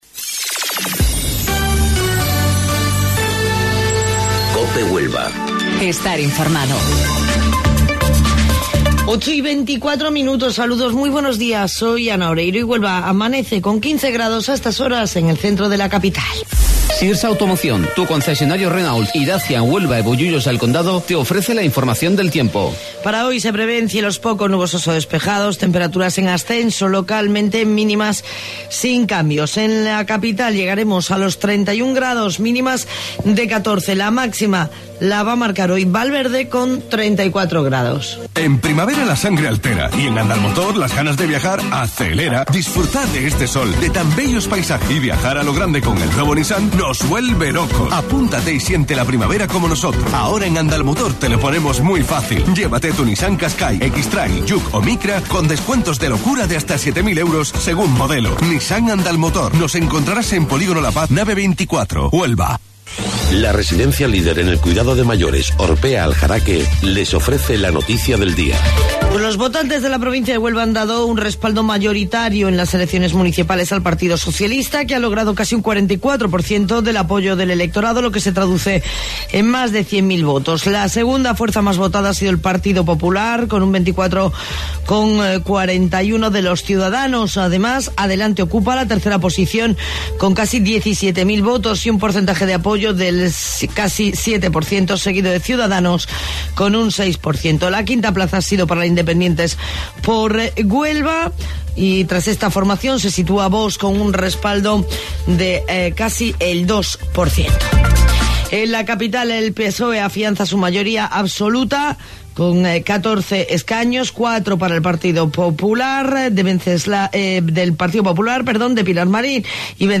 AUDIO: Informativo Local 08:25 del 27 de Mayo